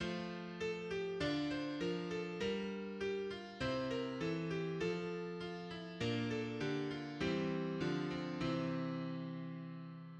A well-known popular example of a threefold descending fifths diatonic sequence is found in the refrain from the Christmas carol "Angels We Have Heard on High,"[4] as illustrated immediately below ("Glo...ria in excelsis Deo"). The one-measure melodic motive is shifted downward at the interval of a second, and the harmonic aspect does so likewise by following the circle of fifths